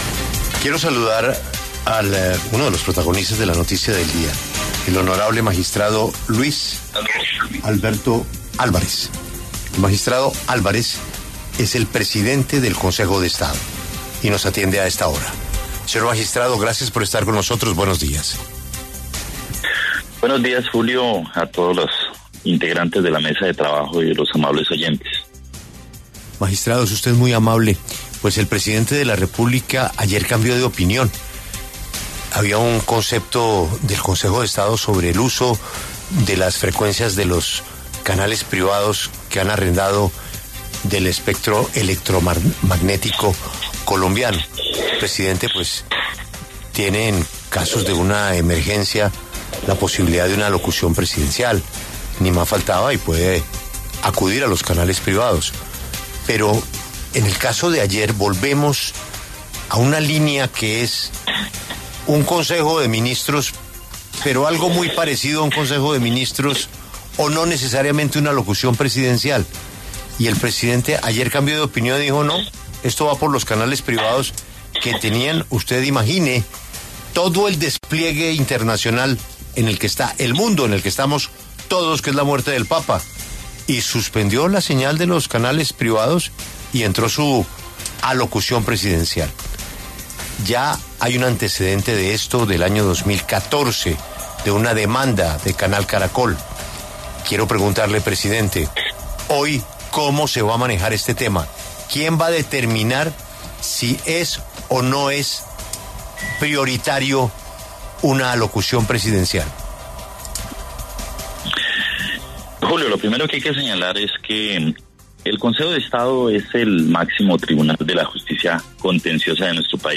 En diálogo con La W, el magistrado Luis Alberto Álvarez precisó que la reserva de los consejos de ministros, dispuesta por ley, se encuentra vigente y añadió que a su juicio la nulidad planteada por la presidencia de la República no existió.